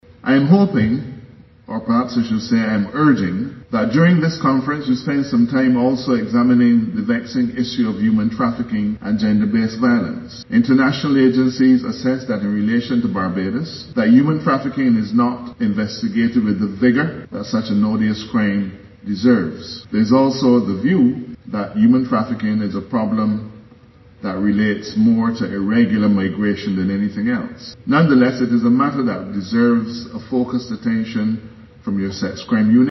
The Attorney General, Dale Marshall, who gave the feature address expressed his satisfaction with the reduction in crime.